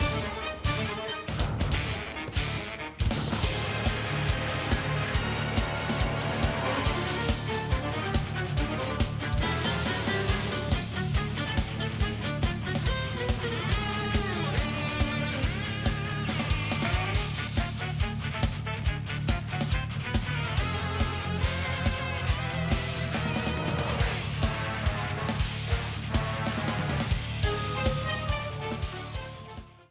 A battle theme
Ripped from the game
clipped to 30 seconds and applied fade-out